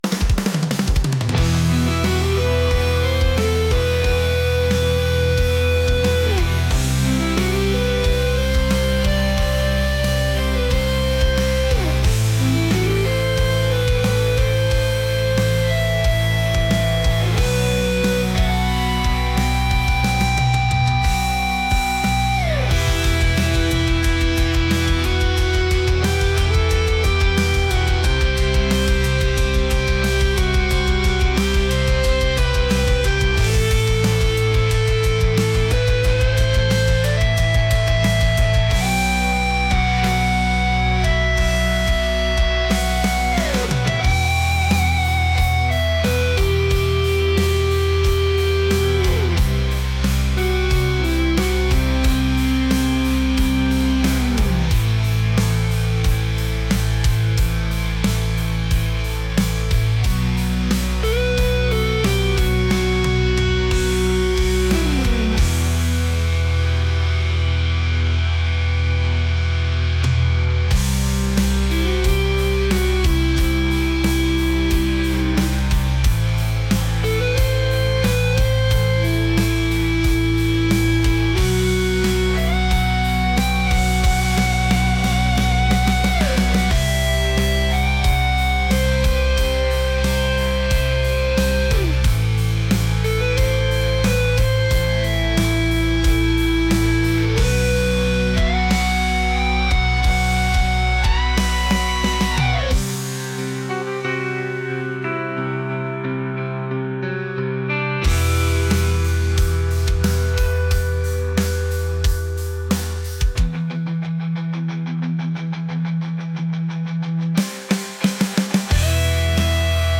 intense | rock